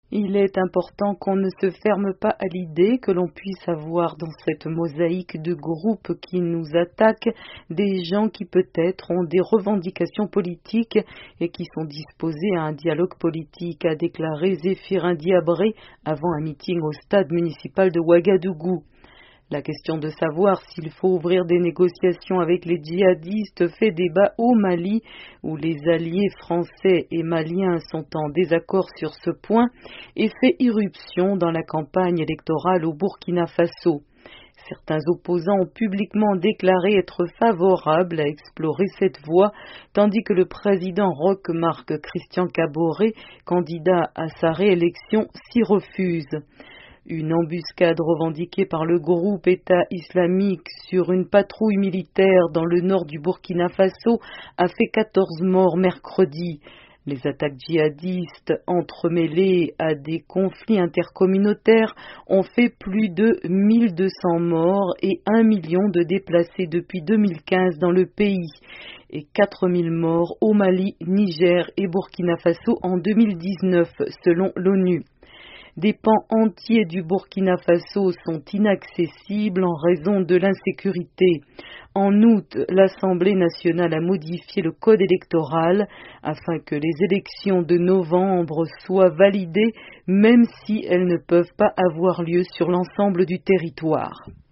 En marge d'un meeting à Ouagadougou, le chef de file de l'opposition burkinabè et candidat à la présidentielle du 22 novembre, Zéphirin Diabré, a déclaré qu'il ne fallait "pas se fermer à l'idée" d'un dialogue avec les groupes jihadistes qui sévissent au Sahel.